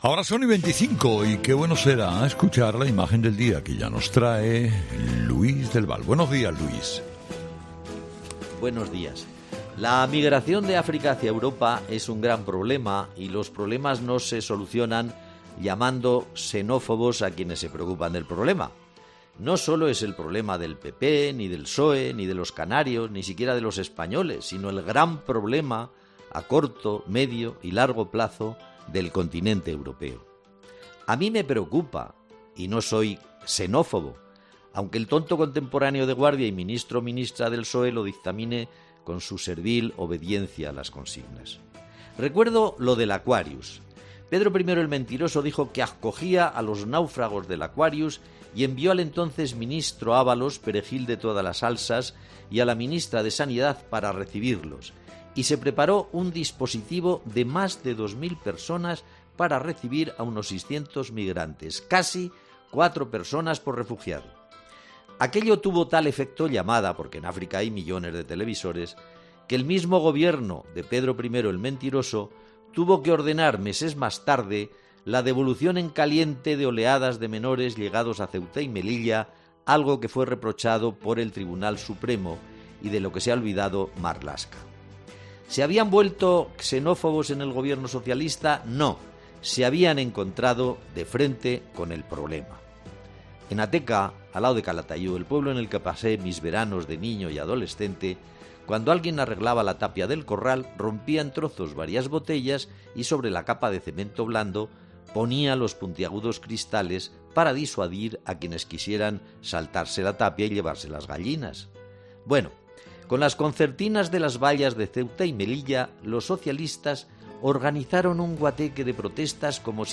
Habla el profesor del gran problema que tenemos con la migración y duda de las soluciones que ofrece el Gobierno